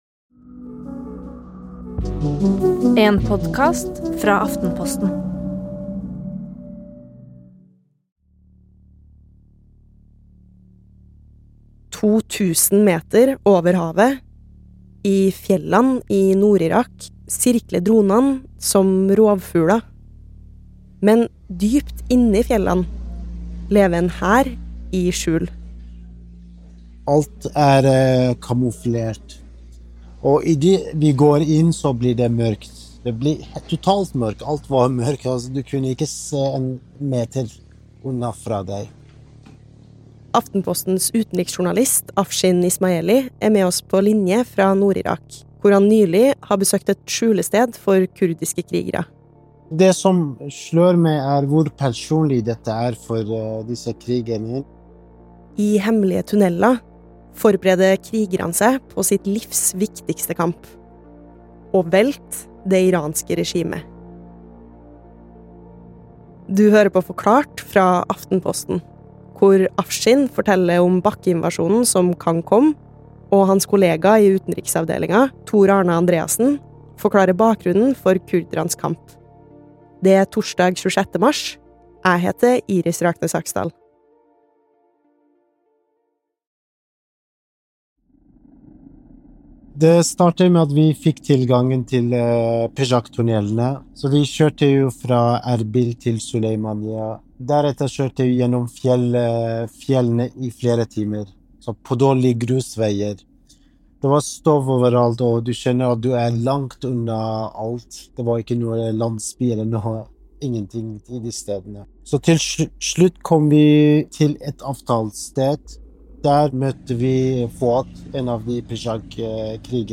Veldig interessant feltreportasje fra Kurdistan, i Aftenpostens podkast Forklart.